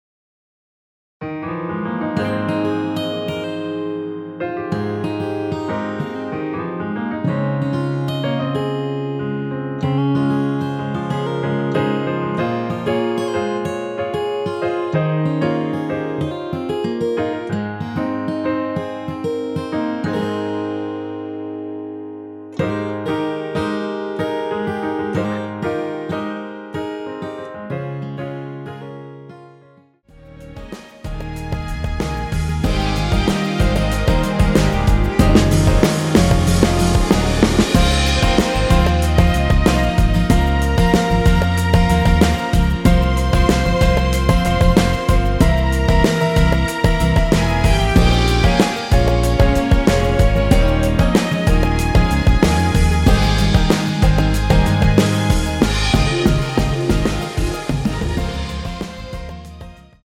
대부분의 여성분이 부르실수 있는 키로 제작 하였습니다.
원키에서(+7)올린 MR입니다.(미리듣기 확인)
Ab
앞부분30초, 뒷부분30초씩 편집해서 올려 드리고 있습니다.
중간에 음이 끈어지고 다시 나오는 이유는